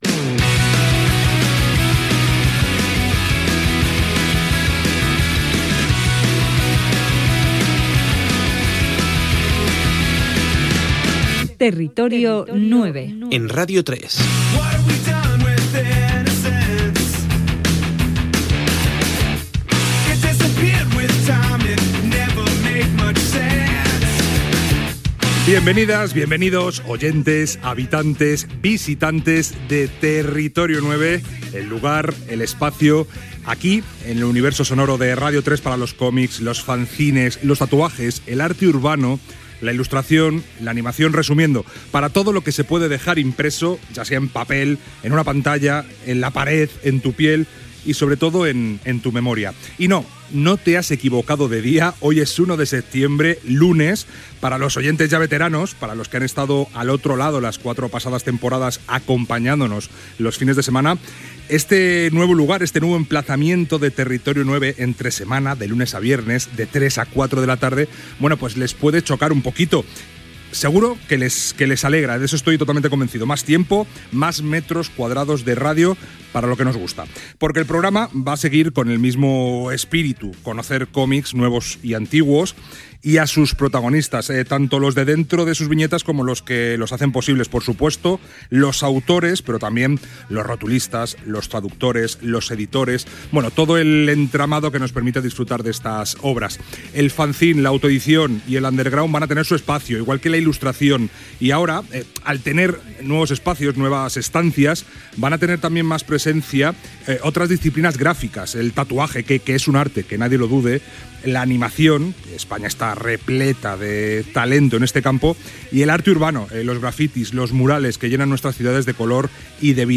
Careta, inici del primer programa de la temporada 2025-2026. Temes del programa (còmics, fanzines, etc.), data i comentari del canvi d'horari.